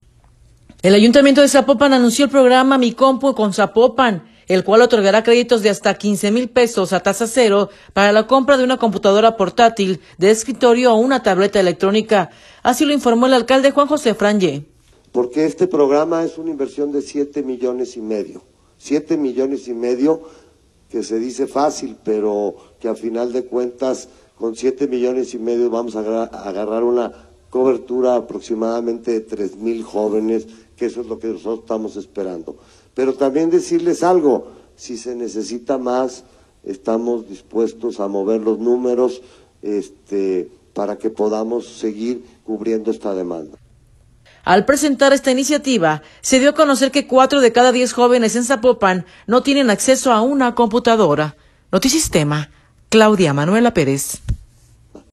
Así lo informó el alcalde Juan José Frangie.